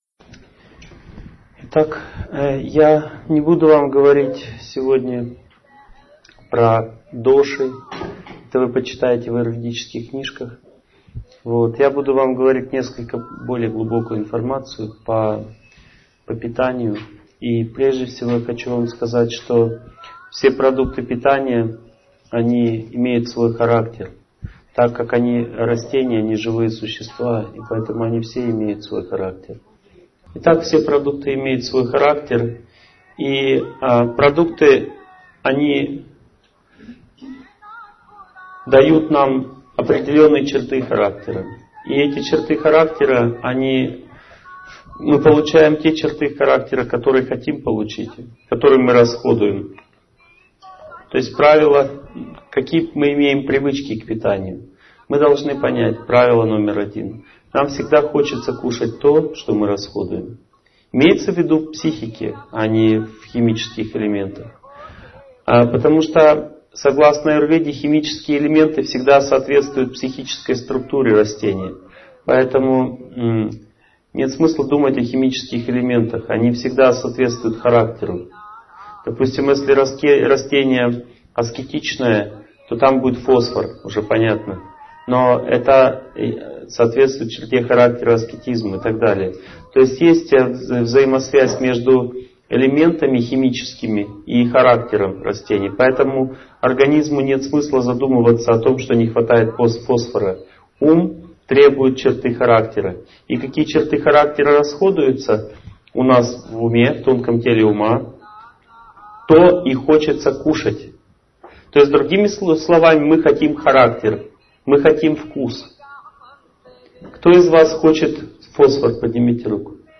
Лекция будет полезна всем, кто хочет улучшить психологическое здоровье.